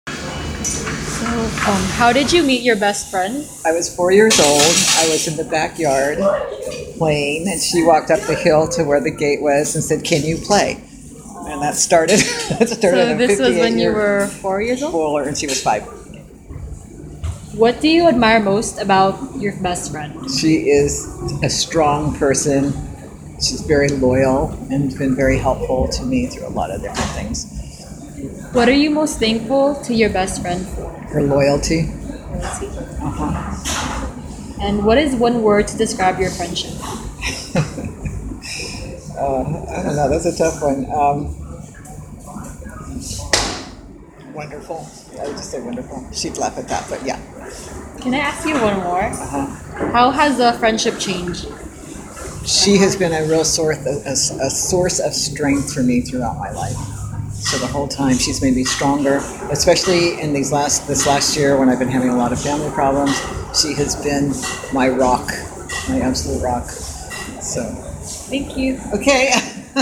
I met my first interviewee inside a laundromat on Noriega Street.